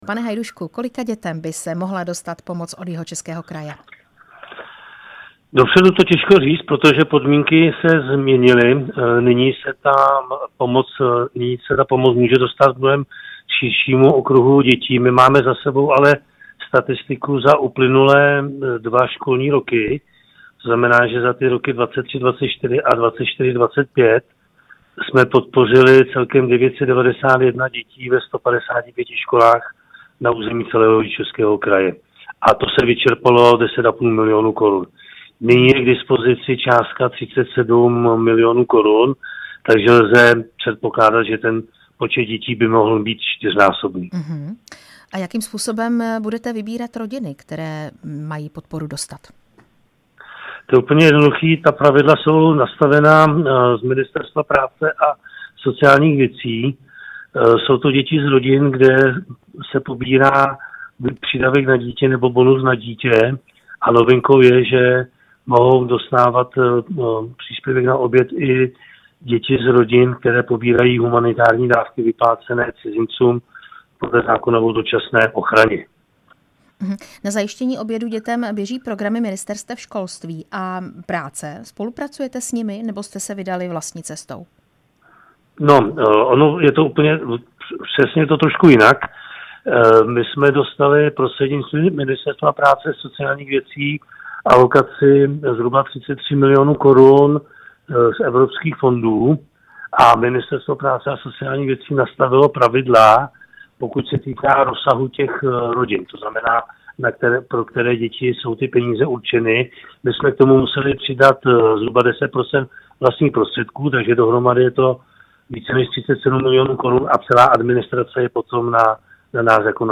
Na pomoc pro ně vyčlenil kraj přes 37 milion korun. Na podrobnosti jsme se ve vysílání Radia Prostor ptali náměstka jihočeského hejtmana Tomáš Hajduška z ODS.
Rozhovor s náměstkem jihočeského hejtmana Tomášem Hajduškem